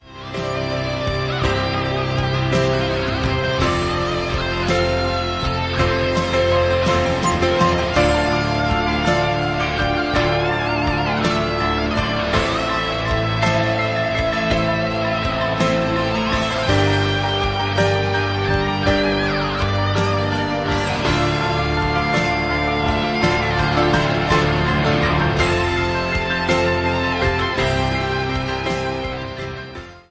The live piano accompaniment that plays with the album
Instrumental